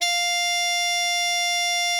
bari_sax_077.wav